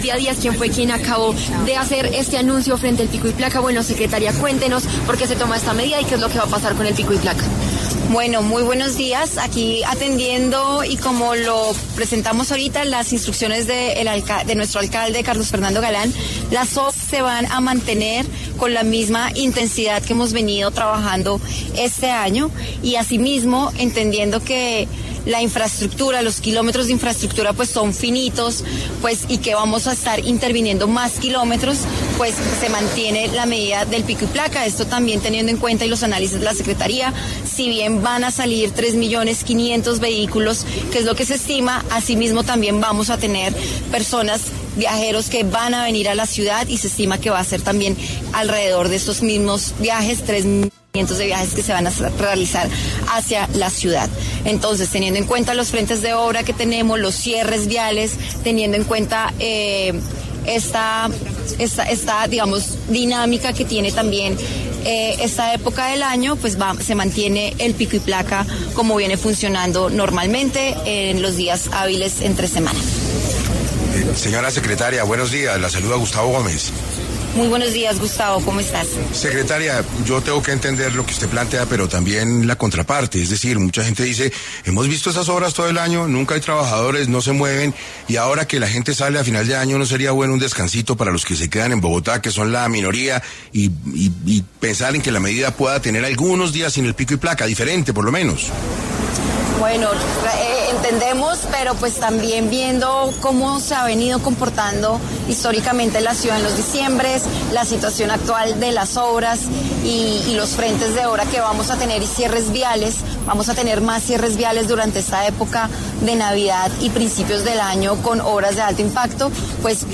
La Alcaldía de Bogotá anunció en la mañana de este lunes que no se levantará el pico y placa, pues seguirá aplicándose entre la última semana diciembre y la primera de enero del 2025. Asimismo, en 6AM de Caracol Radio estuvo Claudia Díaz, secretaria de Movilidad de la capital, quien aclaró porqué decidieron continuar con esta medida durante las festividades de fin de año.